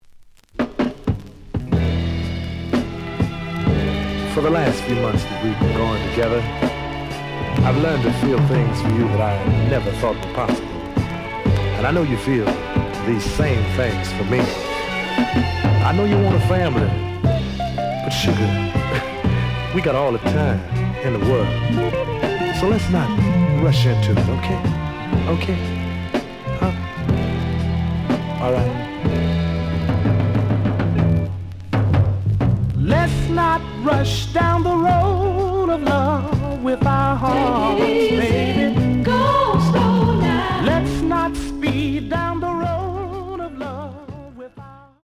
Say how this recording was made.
The audio sample is recorded from the actual item. Edge warp.